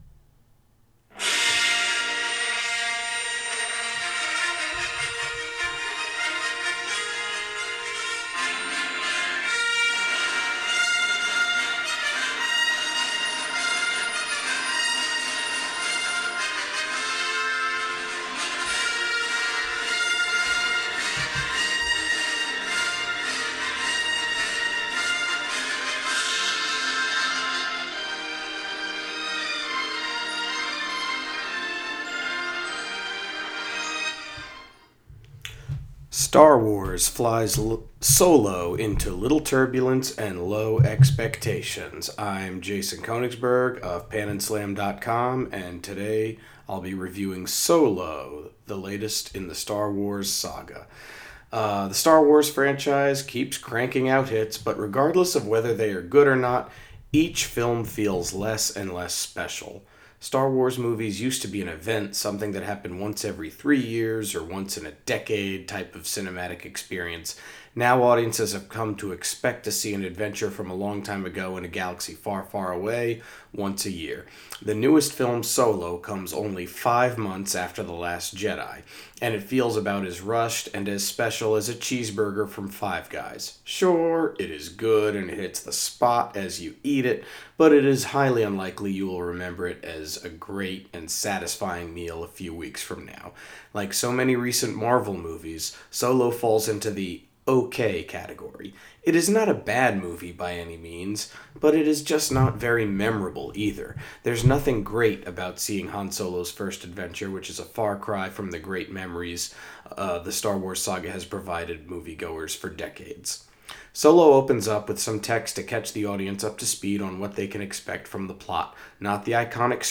Movie Review: Solo